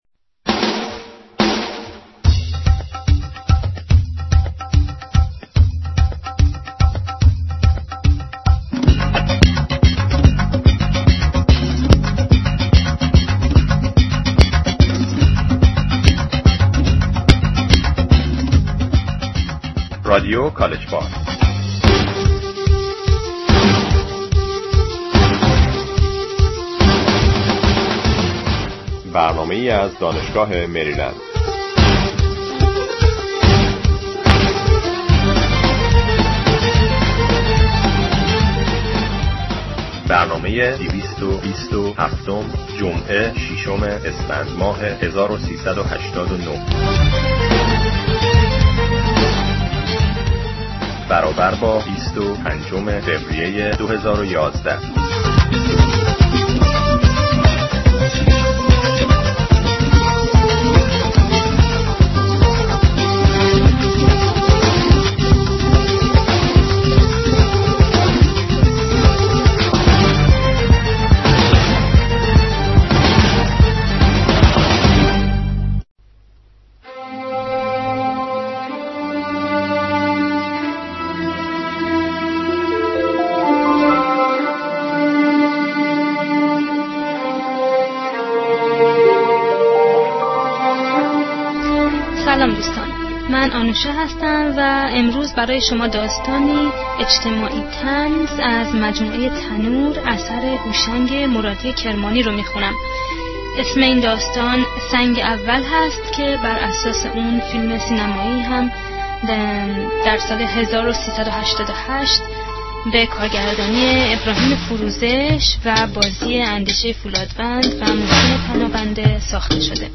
صدای خوب و دلنشین چواننده هم مزید بر علت شد..